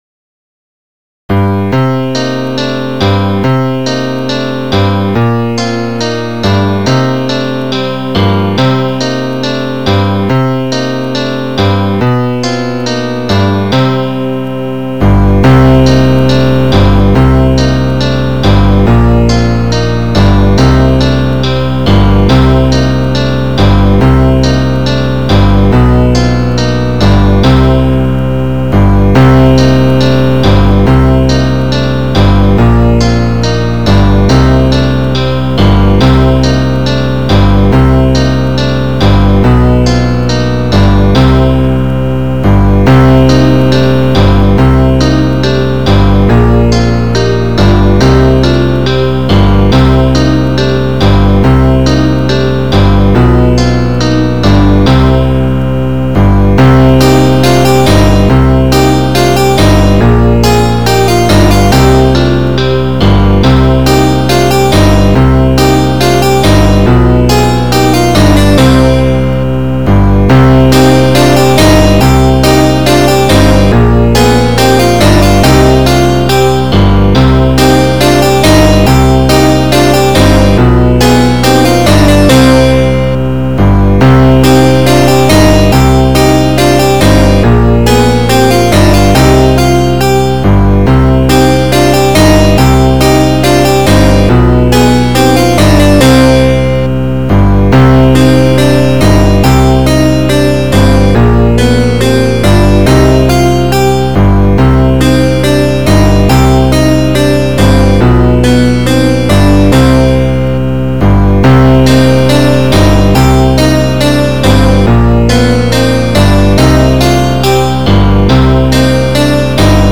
This is some random music I created using lmms. It sounds a bit video game like, so I called it Video Game Music.